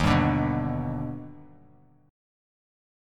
D#+M7 chord